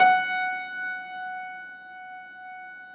53g-pno16-F3.wav